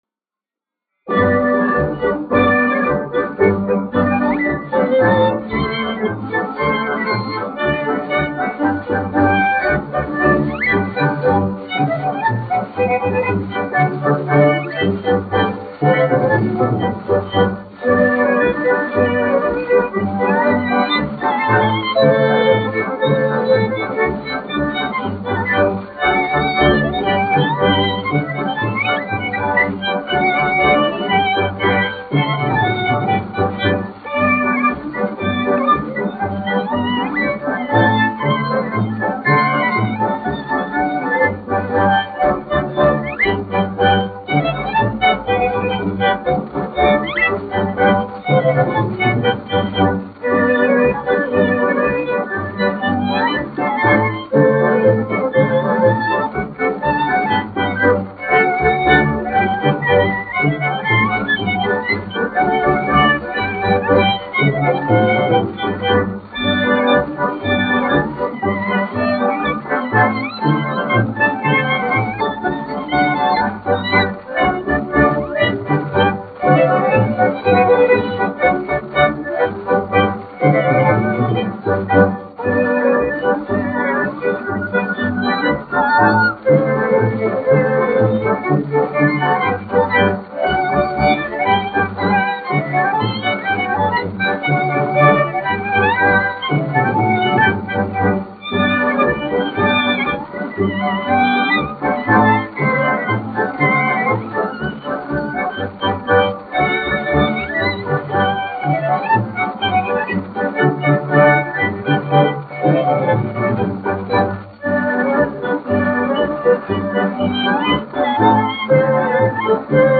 1 skpl. : analogs, 78 apgr/min, mono ; 25 cm
Latviešu tautas dejas
Skaņuplate